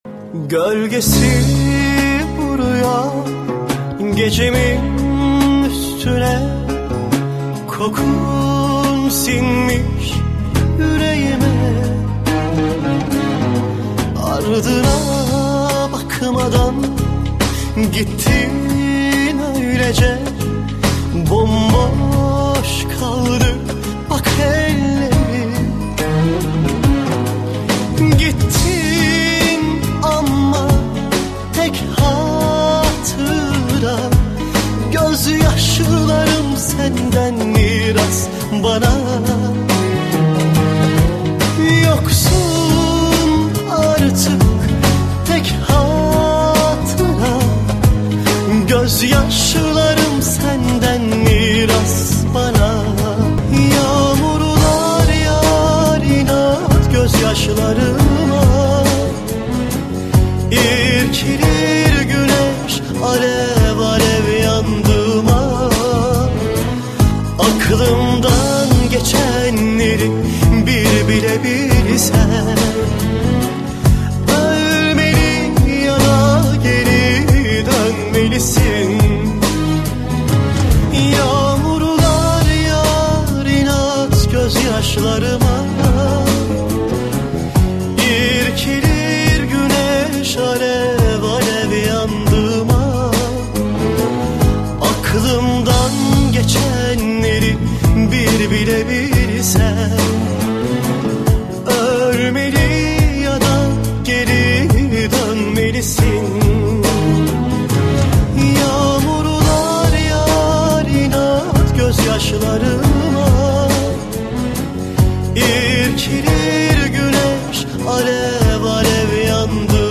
всемирно известный турецкий исполнитель поп-музыки.